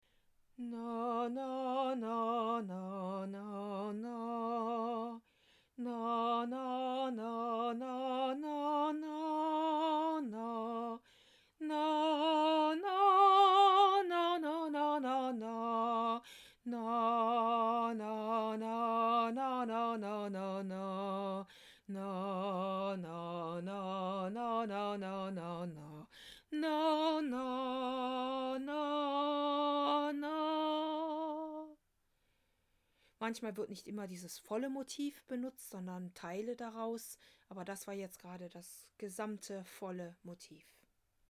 misinai_HH_evening.mp3